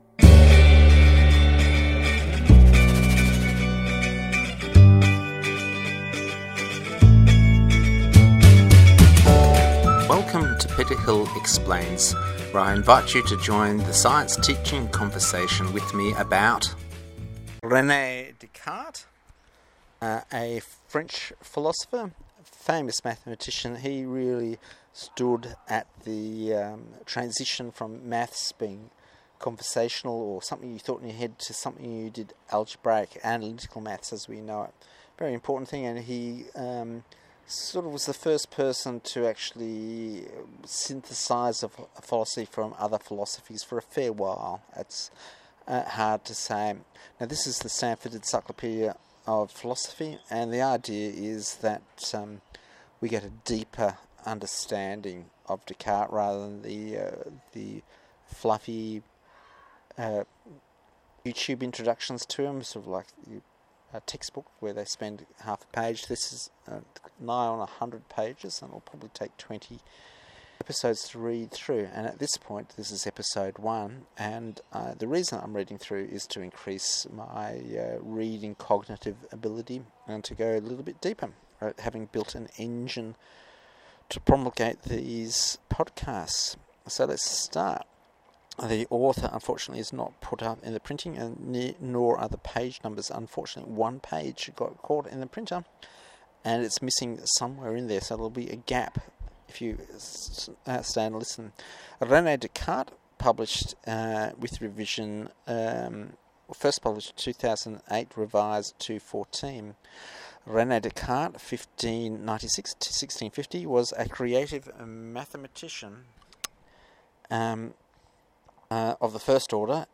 A reading from the Standford Encyclopedea of Phyilosophy, An orientation and early life. http